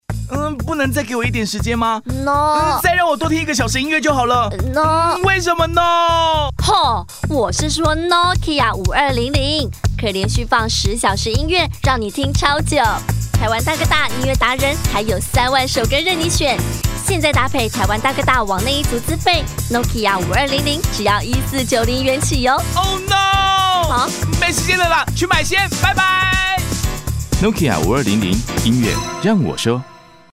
國語配音 男性配音員
撒嬌男-Nokia
撒嬌男-Nokia.mp3